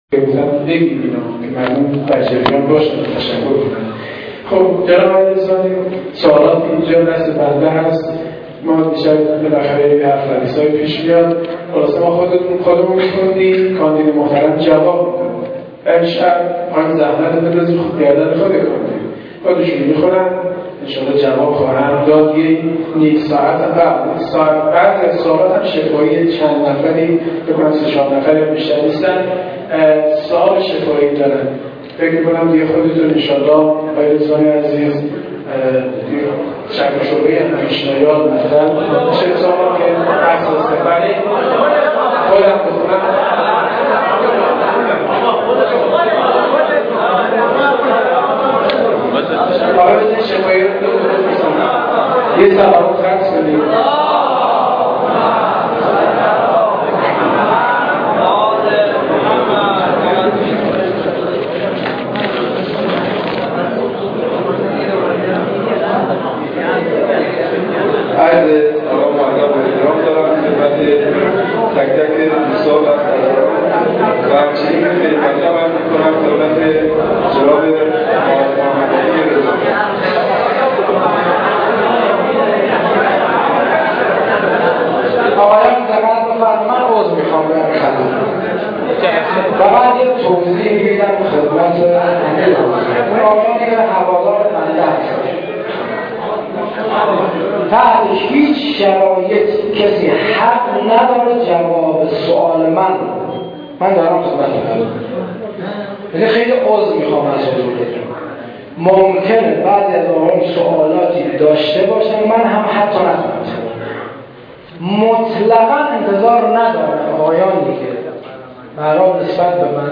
سوالات مردمی و پاسخ به سوالات(قسمت سوم)